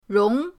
rong2.mp3